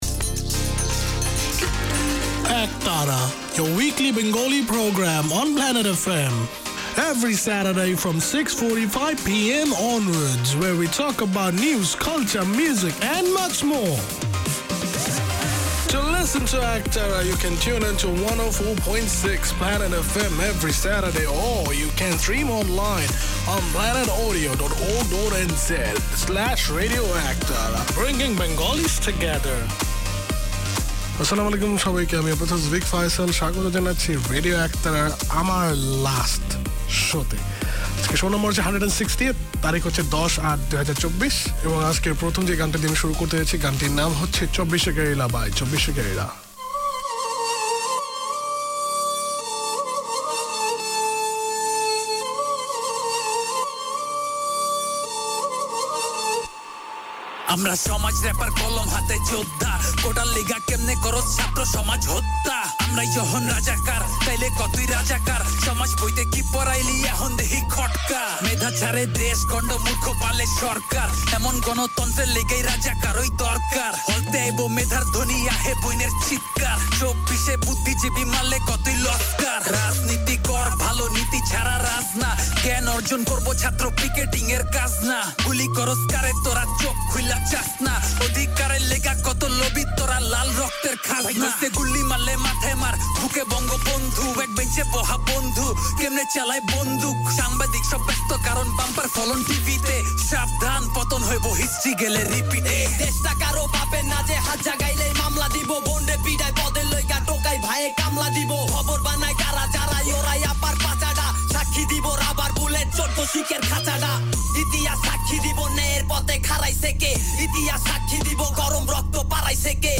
Produced by and for the Bangladeshi community in Auckland, Radio Ektara features current affairs, community notices, music and interviews to entertain and inform.